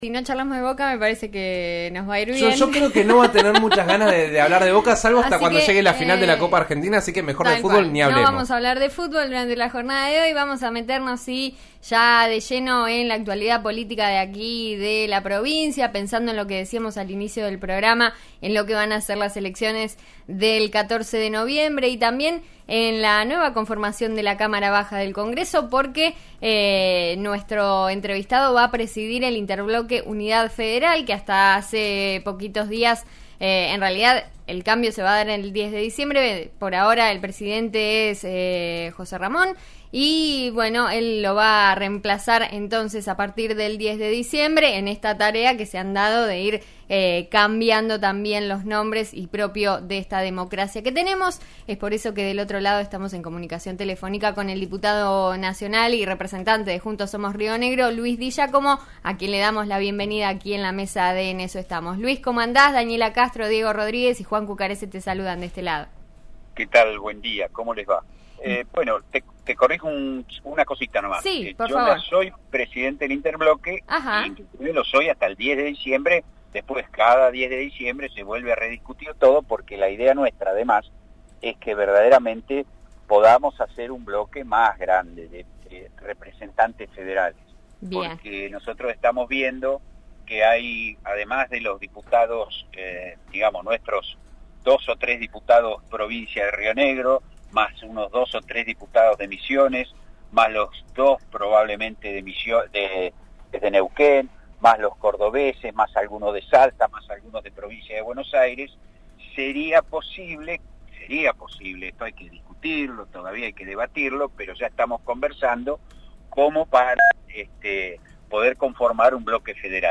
El diputado por Juntos Somos Río Negro conversó con RN RADIO y se mostró confiado sobre lo que puede pasar en las generales. Señaló que el objetivo es meter a los dos candidatos de la fuerza, Agustín Domingo y Mercedes Iberó.